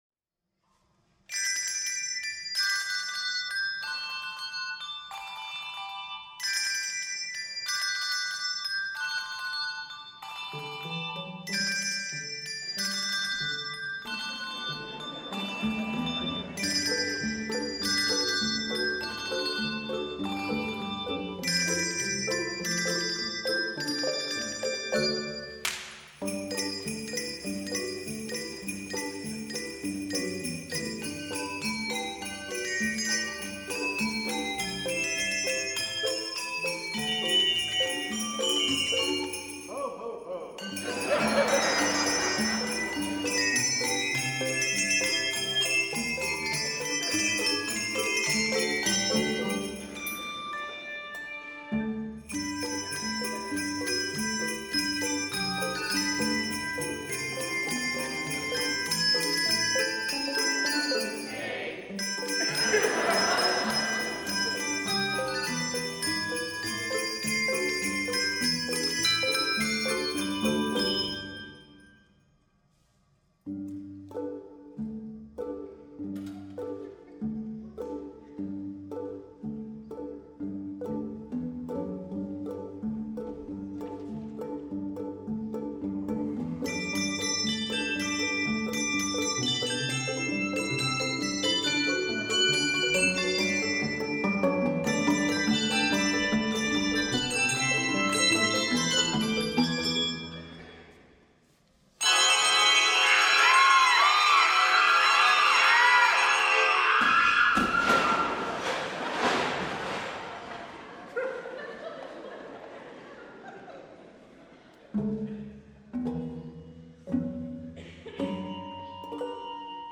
Voicing: Handbells 5-7 Octave